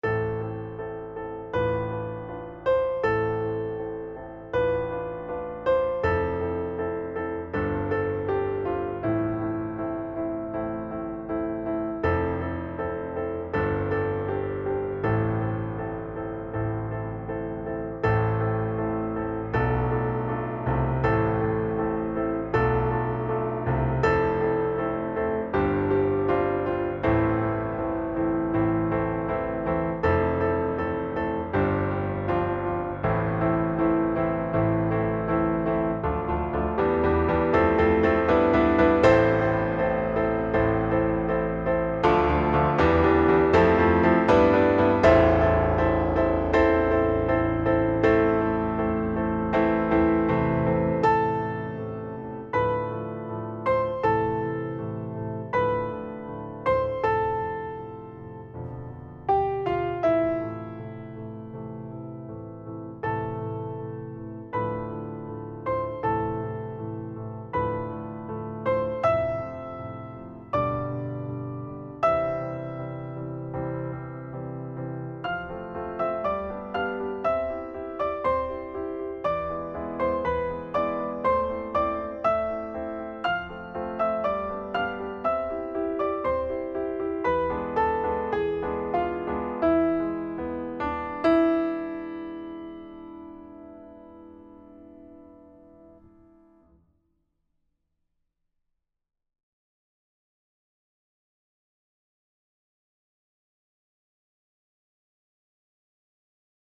Faire ressortir la mélodie, faire chuchoter les accords.
• Tonalité : La mineur
• Mesure(s) : 2/2 et 4/4